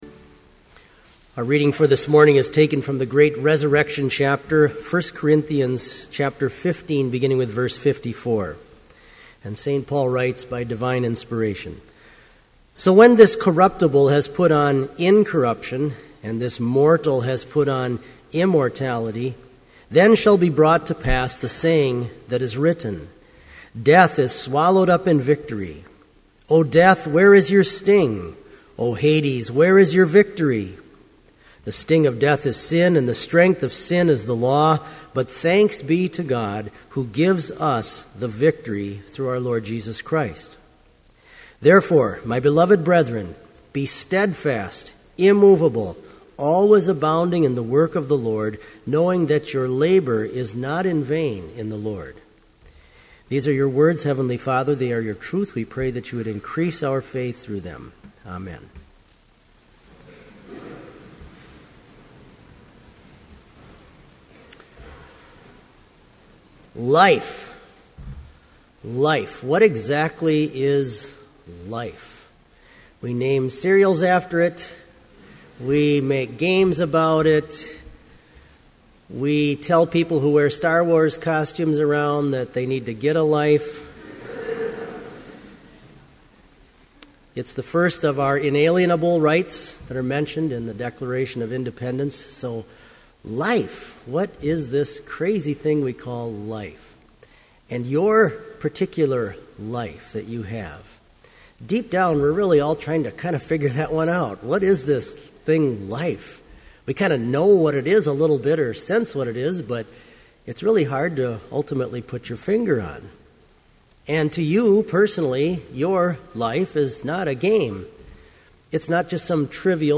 Complete service audio for Chapel - April 11, 2012